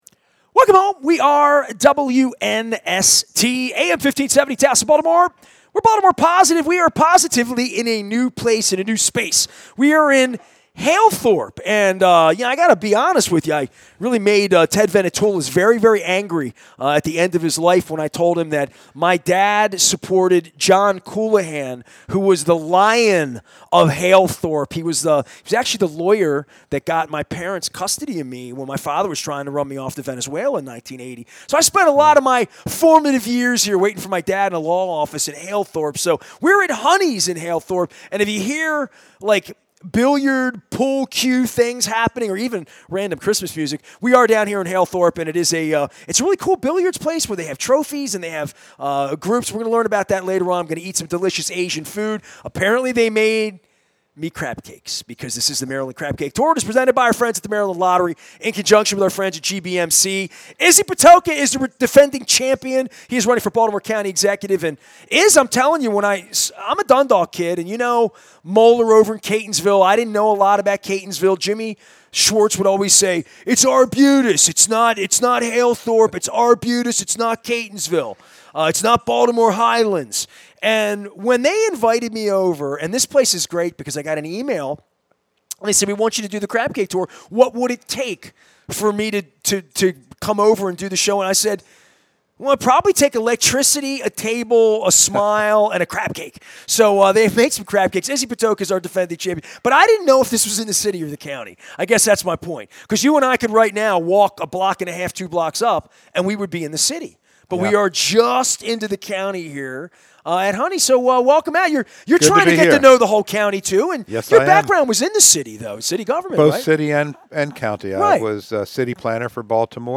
Maryland Crab Cake Tour at Honey's in Halethorpe